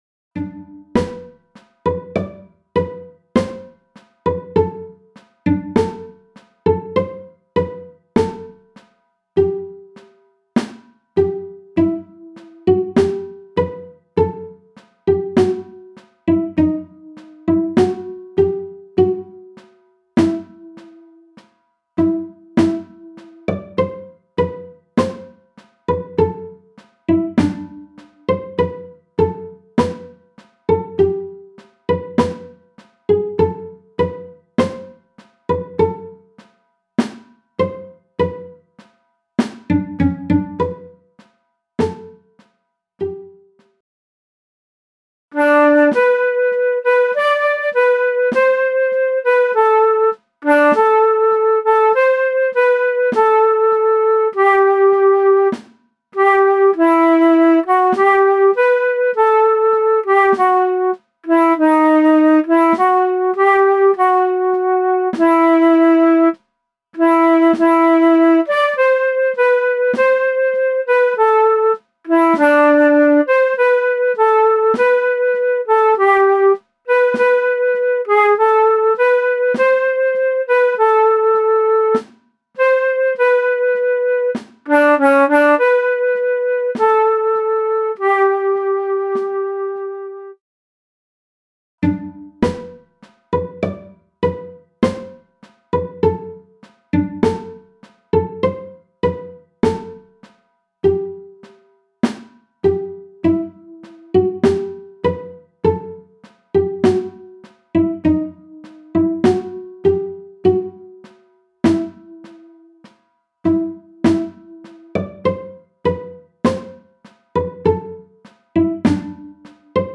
MIDI - 1-stimmig
midi_sommerglanz_1-stimmig_320.mp3